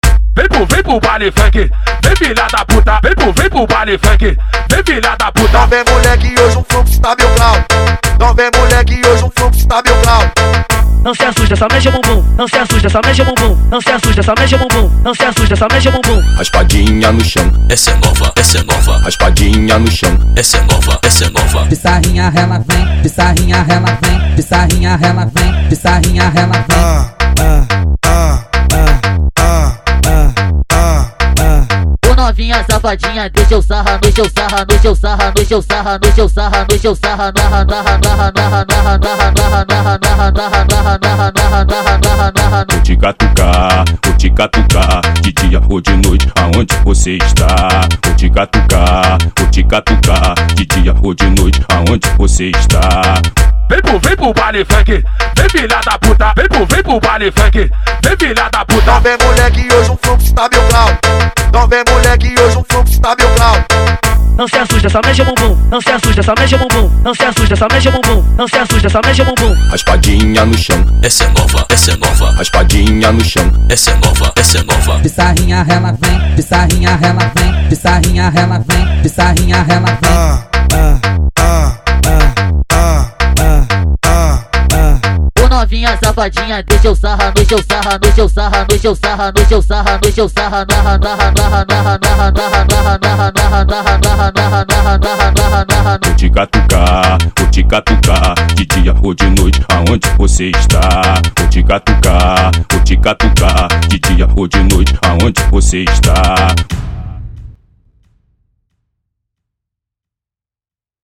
Tecno Melody